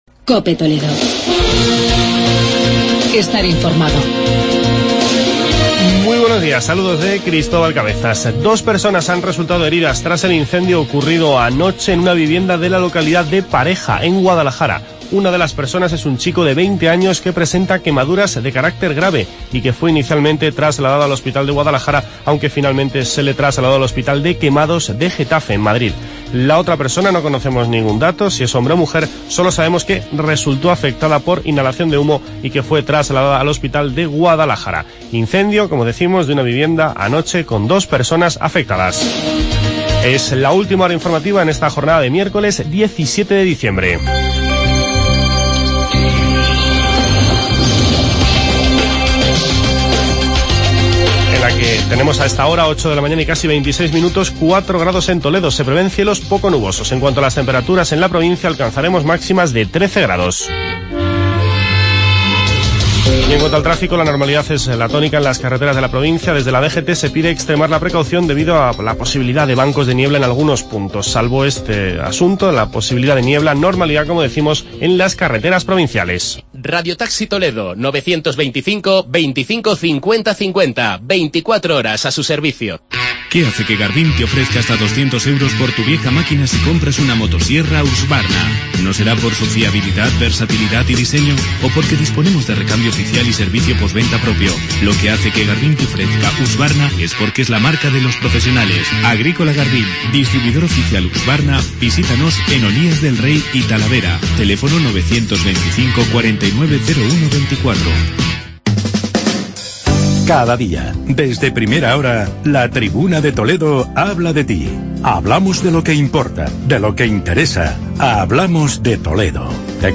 INFORMATIVO MATINAL